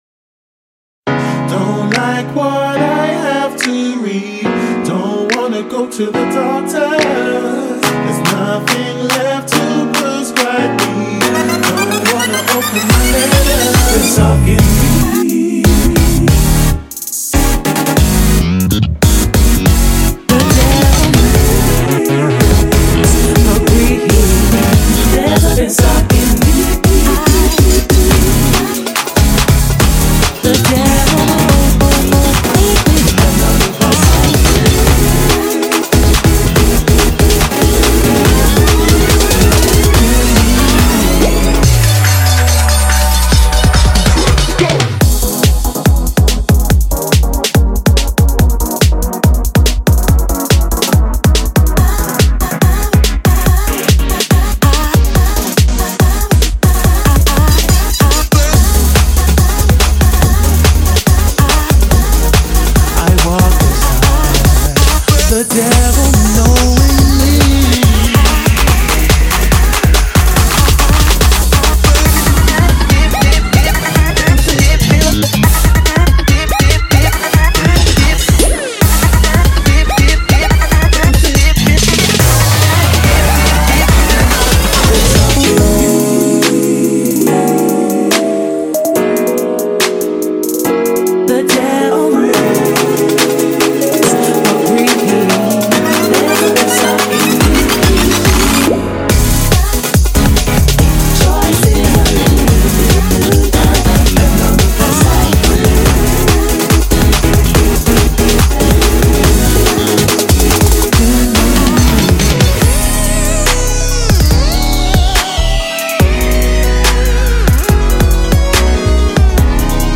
Audio QualityPerfect (Low Quality)